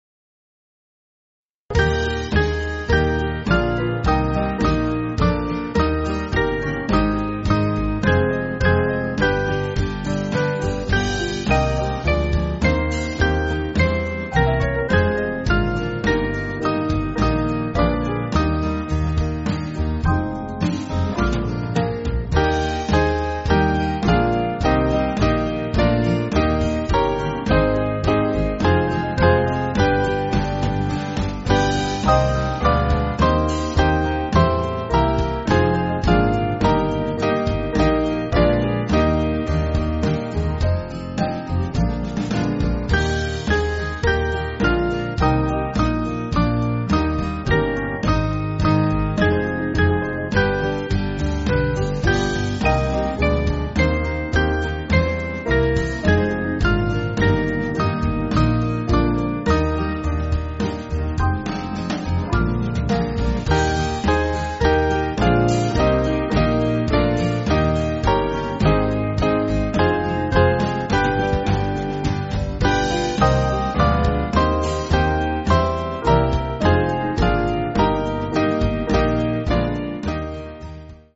Small Band
(CM)   5/Eb